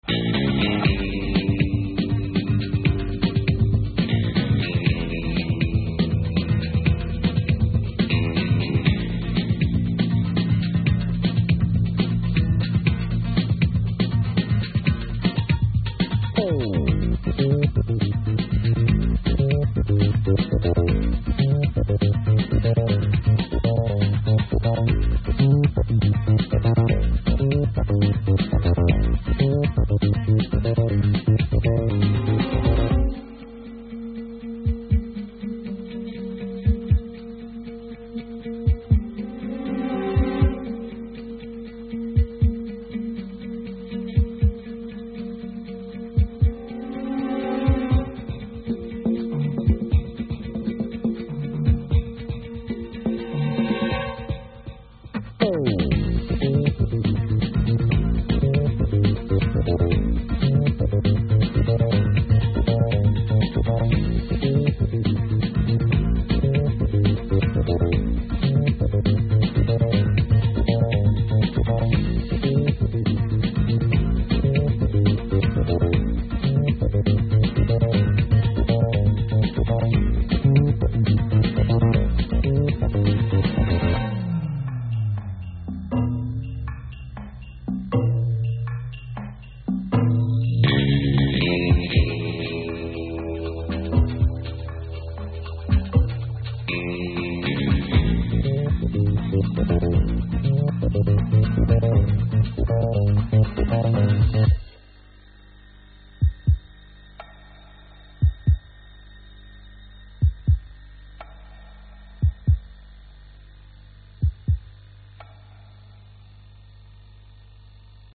Music clip two 1'58" (462Kb) [end credits]